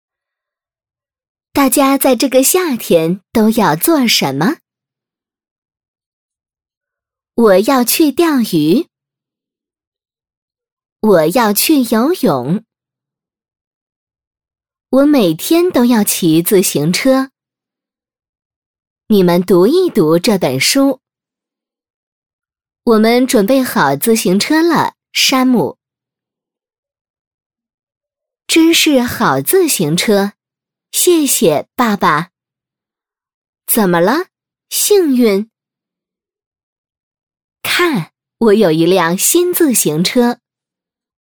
女150-角色旁白【山姆和小狗幸运】
女150-中英双语 成熟知性
女150-角色旁白【山姆和小狗幸运】.mp3